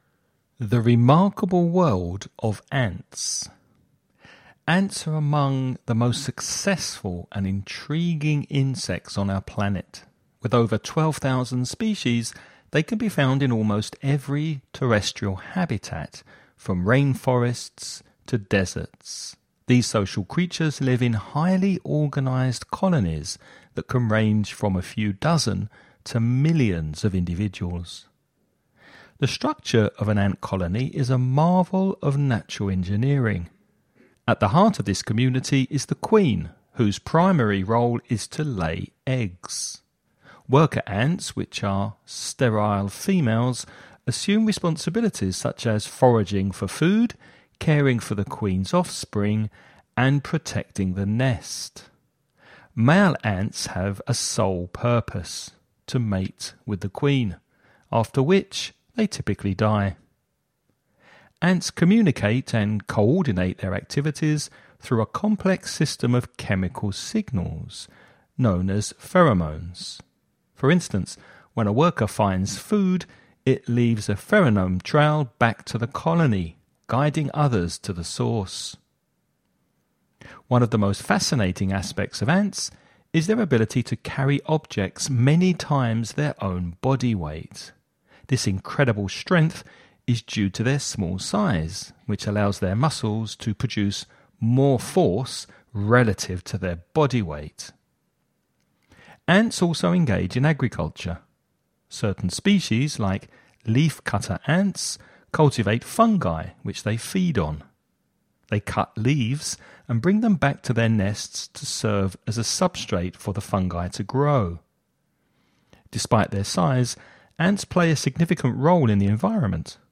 Listening Practice
Before you listen to a man talking about ants, read the following questions..